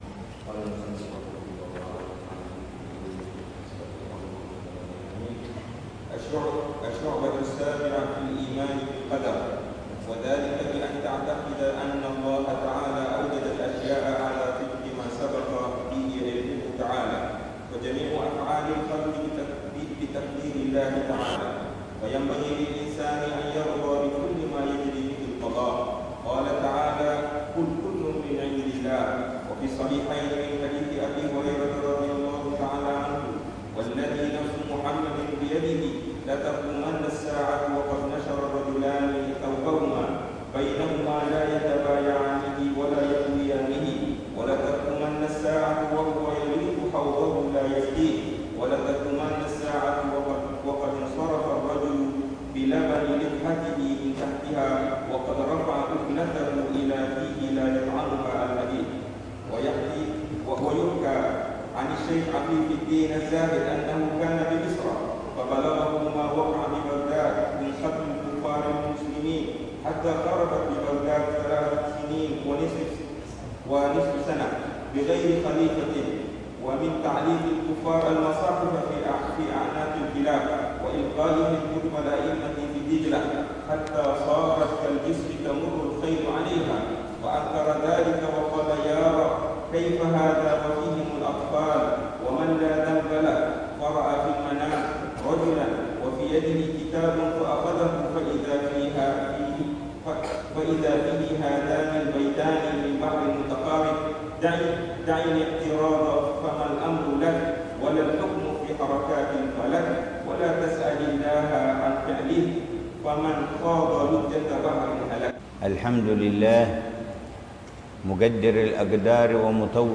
الدرس الثالث للعلامة الحبيب عمر بن حفيظ في شرح كتاب: قامع الطغيان على منظومة شعب الإيمان، للعلامة محمد نووي بن عمر البنتني الجاوي، في المسجد ا